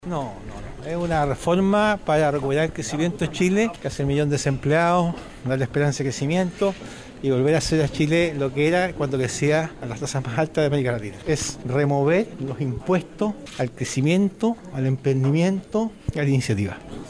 Ante los dardos, el secretario de Estado fue claro, descartando de lleno esa posibilidad.